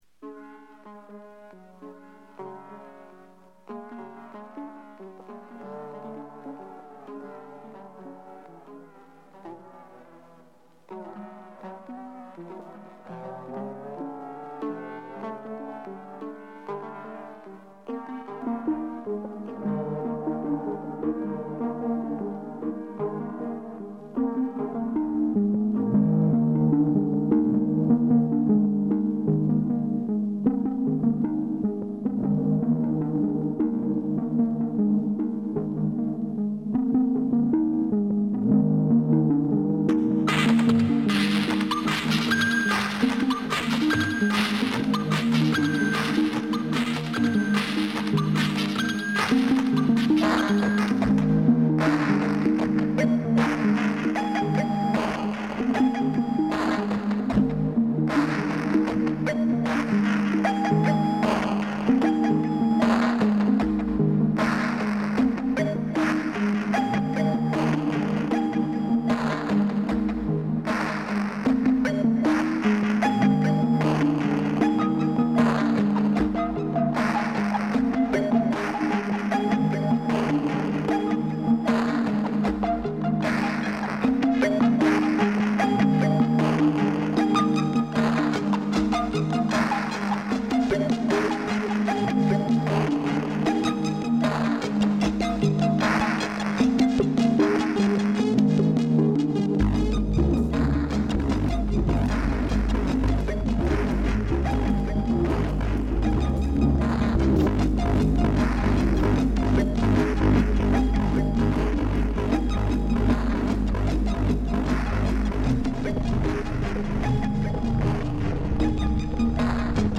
more mlre exercises: (mistakes included)